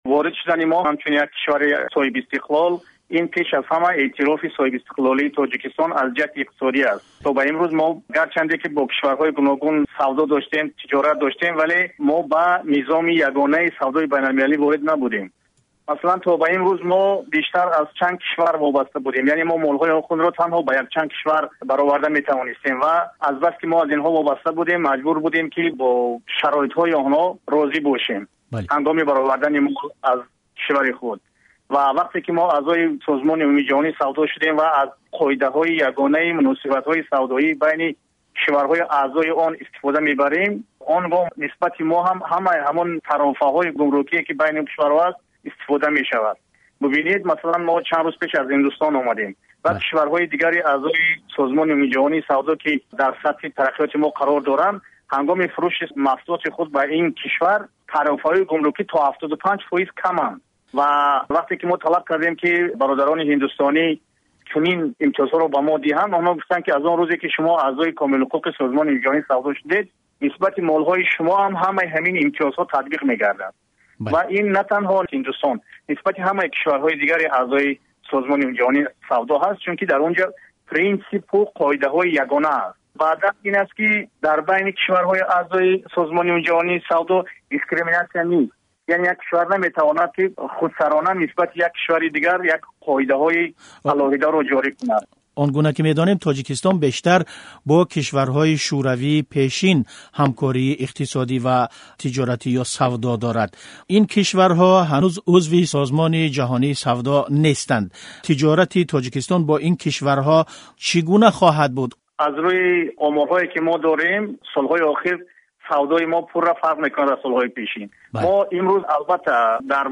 Мусоҳиба бо Шариф Раҳимзода, вазири рушди иқтисоди Тоҷикистон